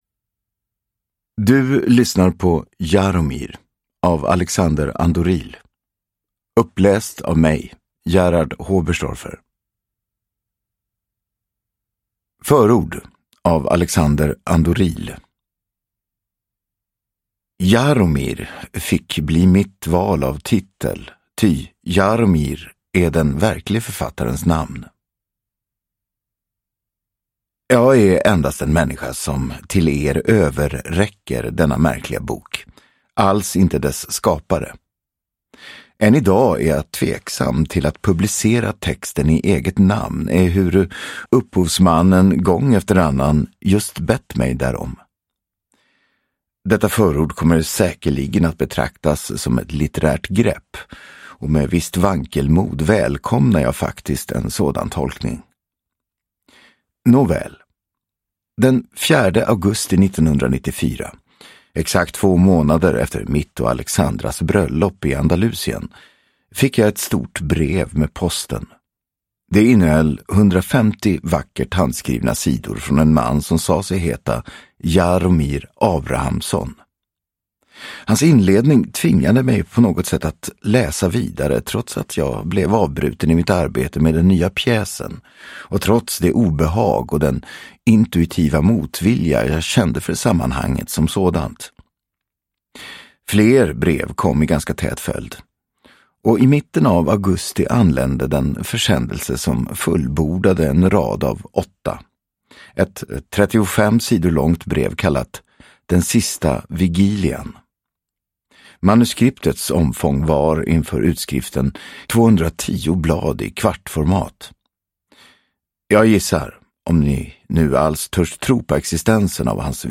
Jaromir (ljudbok) av Alexander Ahndoril